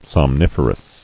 [som·nif·er·ous]